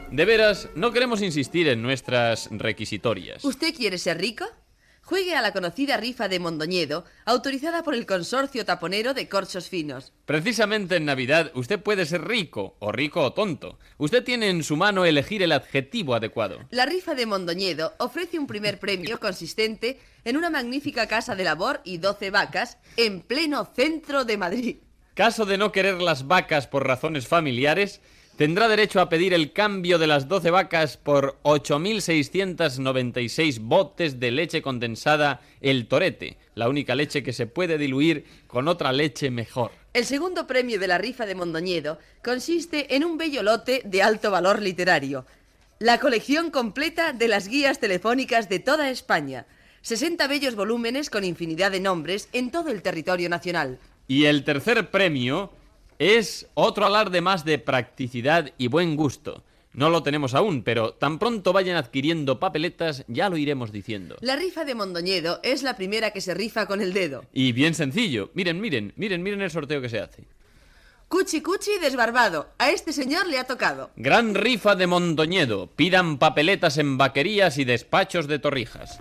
Publicitat fictícia de "La Rifa de Mondoñedo".